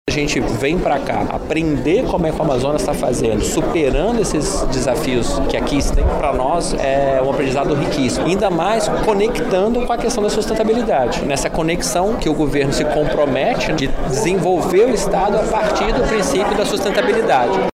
Durante o evento, o secretário nacional de Gestão e Inovação do Ministério de Gestão, Inovação e Serviços Públicos, Roberto Pojo; disse que o Amazonas está ensinado como desenvolver políticas de sustentabilidade.